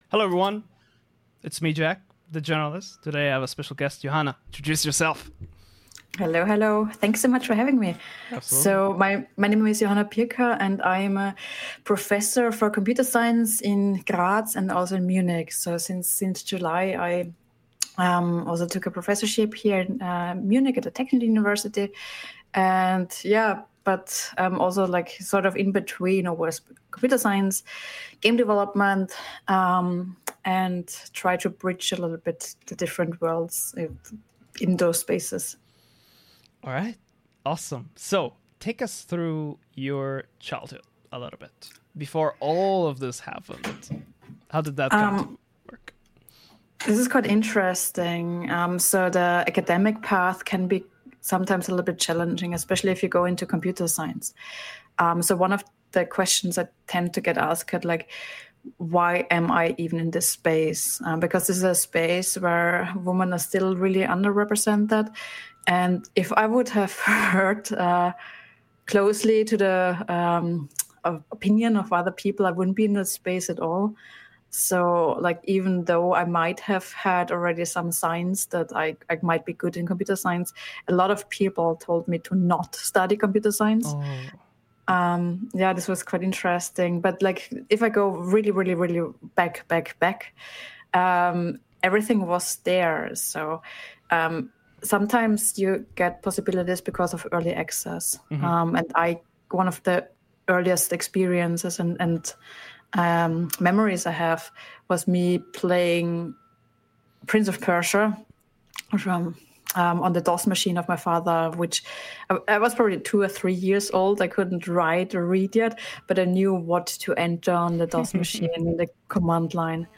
Interviews people who know a little about a lot!